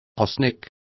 Also find out how arsenico is pronounced correctly.